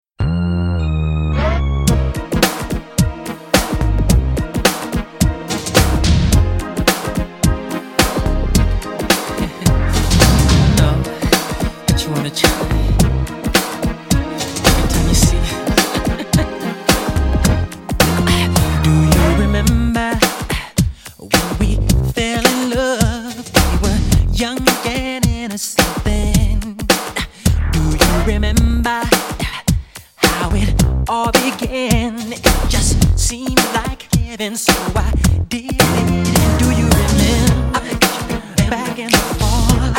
rnb
поп